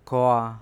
4-s1-kraa-casual.wav